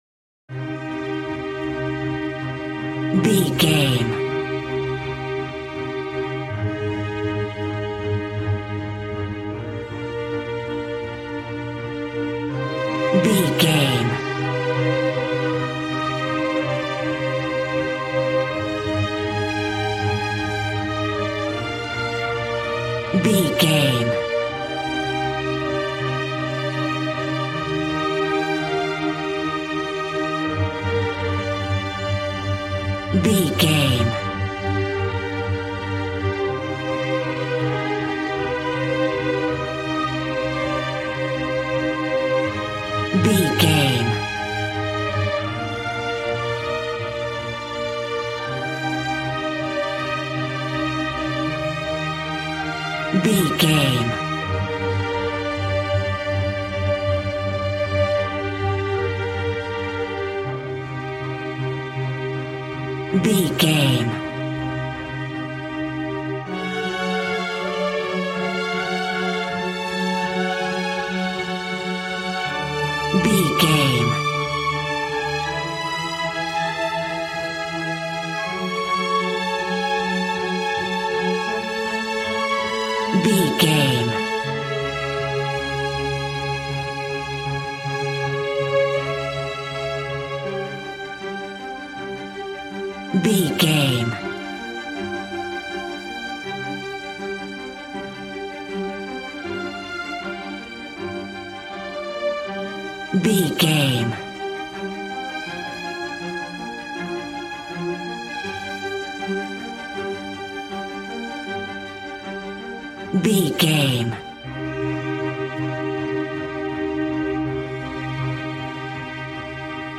A classical music mood from the orchestra.
Regal and romantic, a classy piece of classical music.
Aeolian/Minor
regal
cello
violin
strings